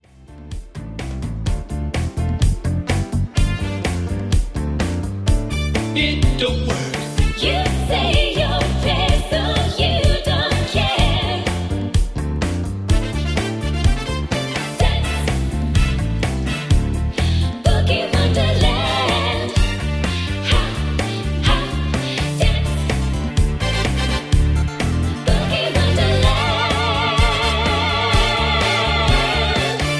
Em
MPEG 1 Layer 3 (Stereo)
Backing track Karaoke
Pop, Disco, 1970s